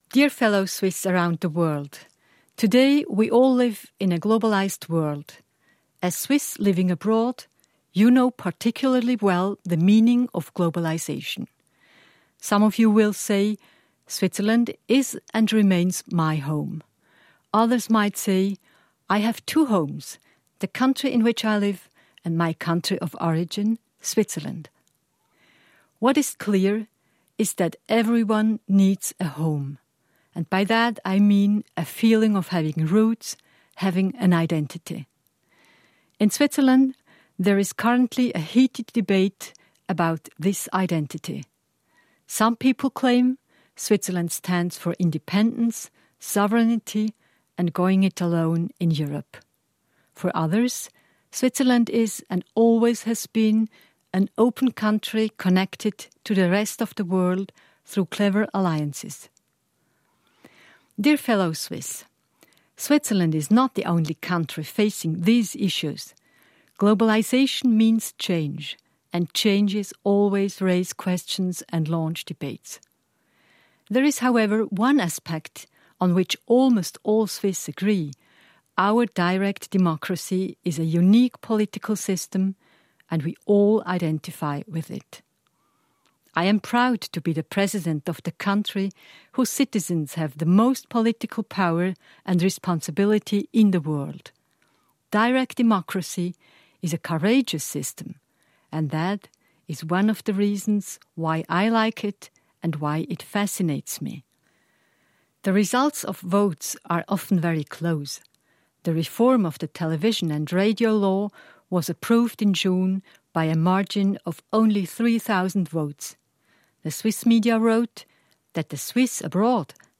President Simonetta Sommaruga’s speech to the Swiss abroad on the Swiss national day